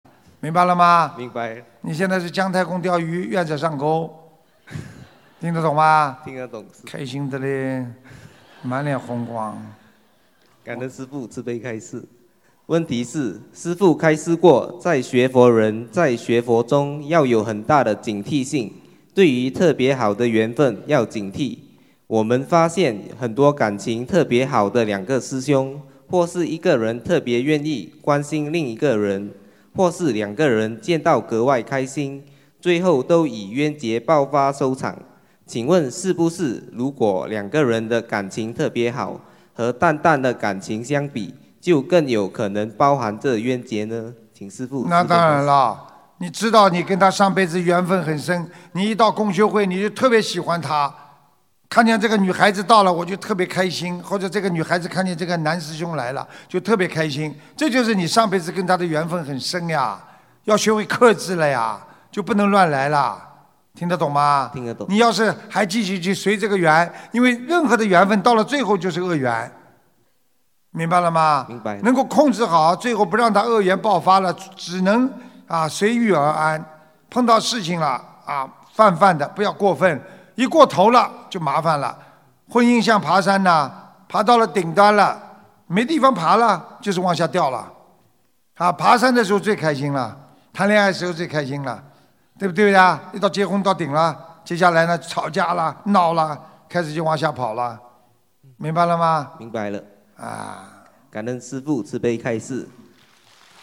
Pertanyaan di Seminar Dharma
Pertanyaan di Acara Pertemuan Umat Buddhis Sedunia di Auckland, 8 November 2019